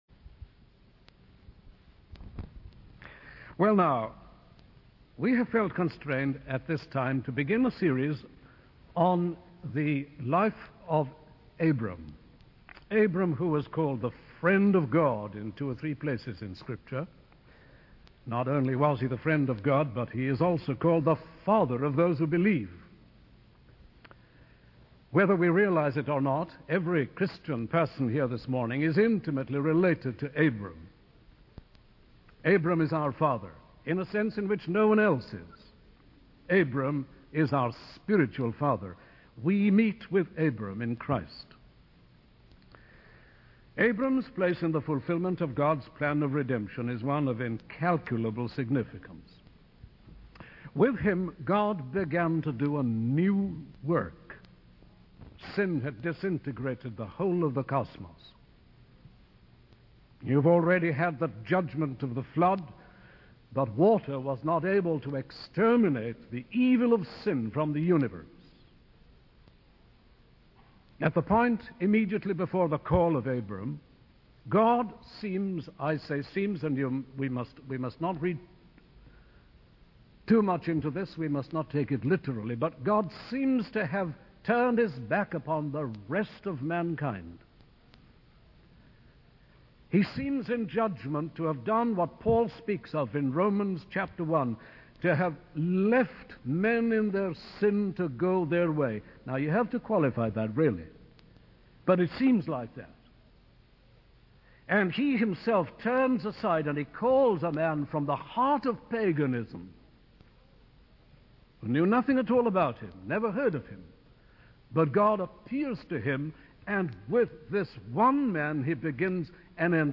In this sermon, the preacher emphasizes the importance of truly listening and meditating on the truths of God's word.